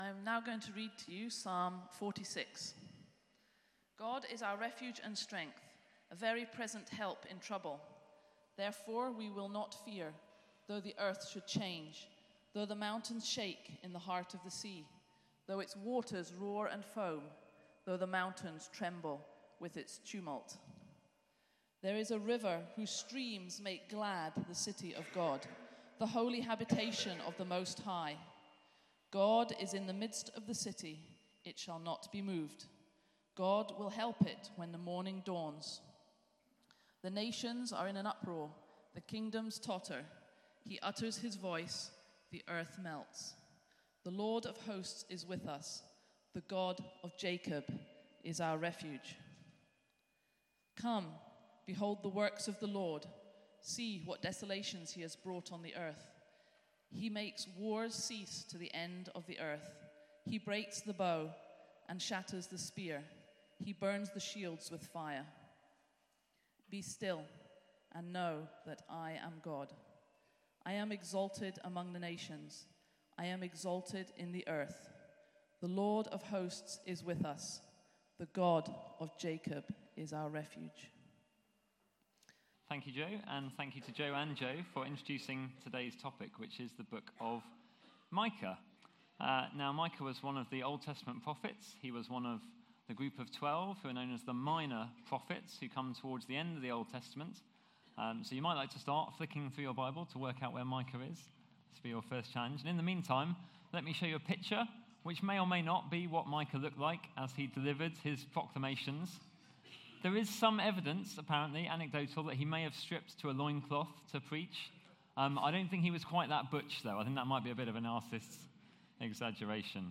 Media for Sunday Service on Sun 27th Nov 2016 10:00